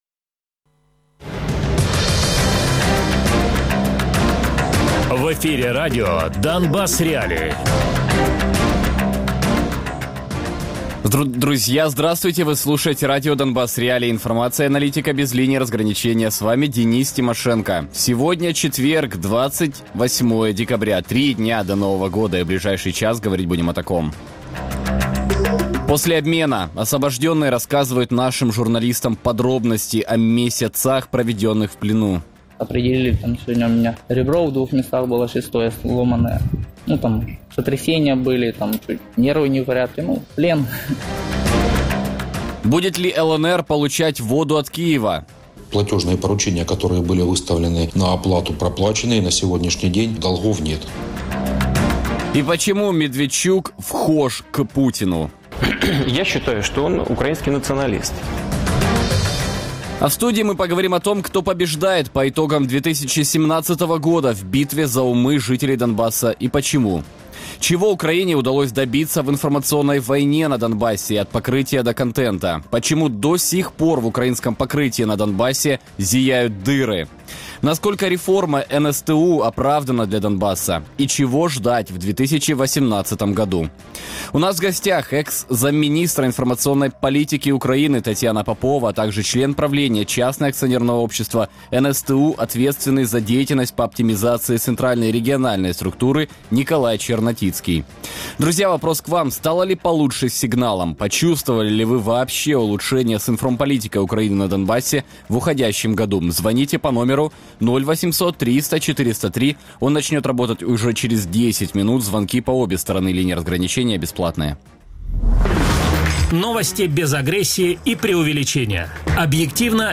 Радіопрограма «Донбас.Реалії»